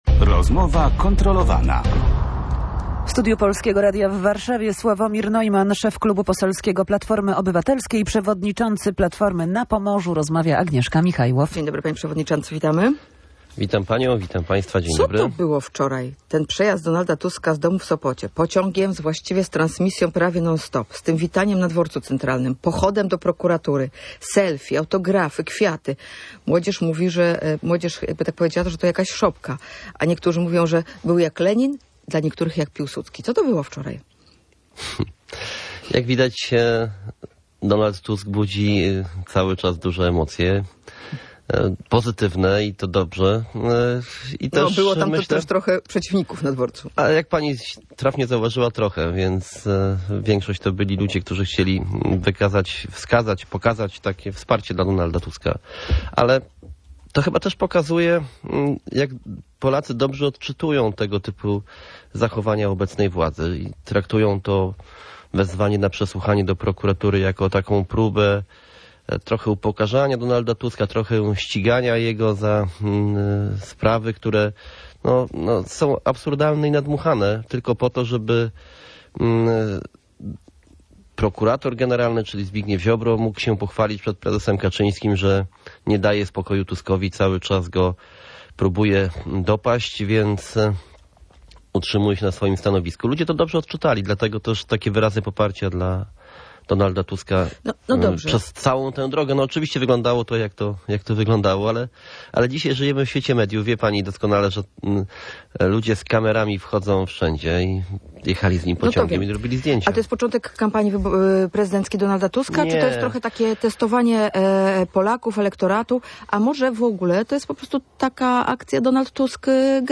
– To nie początek kampanii prezydenckiej Donalda Tuska. Ludzie po prostu dobrze odczytują nagonkę Prawa i Sprawiedliwości – mówił na antenie Radia Gdańsk Sławomir Neumann.
Szef parlamentarnego klubu Platformy Obywatelskiej, przewodniczący partii na Pomorzu był gościem Rozmowy Kontrolowanej.